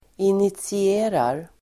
Uttal: [initsi'e:rar]
initierar.mp3